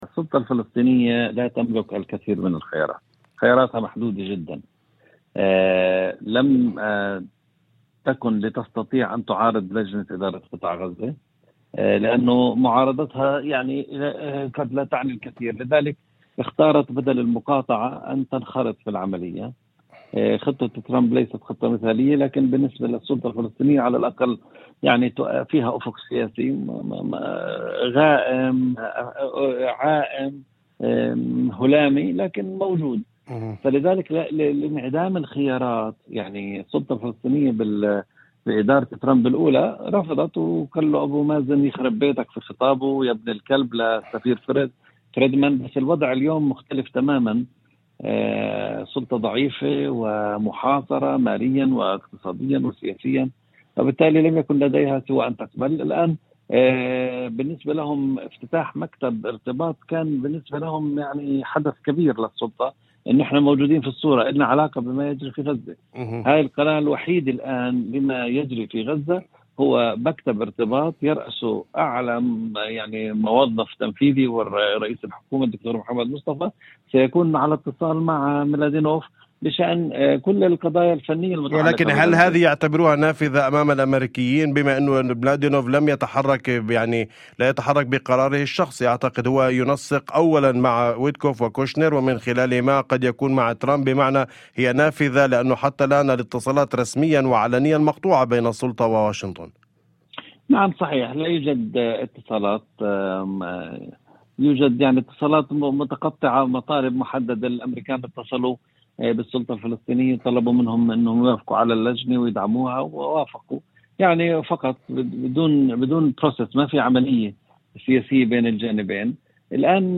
في مداخلة هاتفية